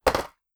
clamour7.wav